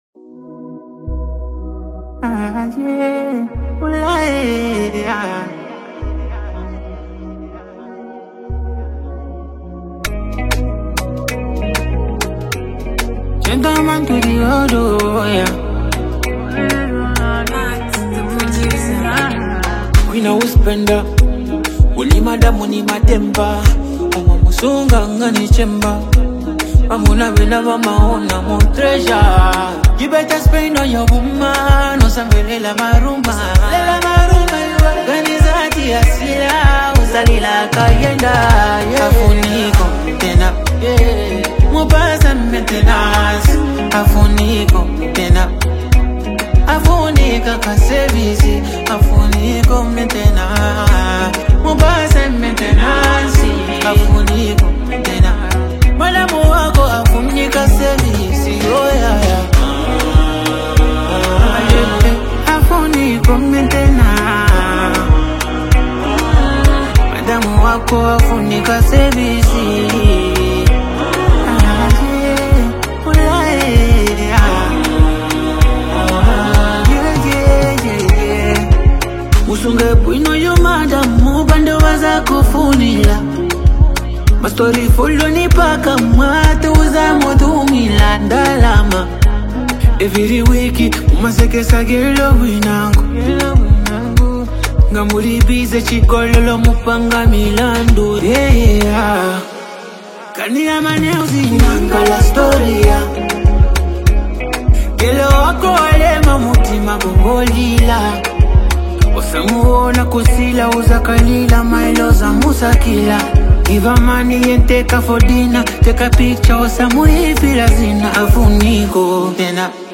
smooth, reflective track that blends melodic vibes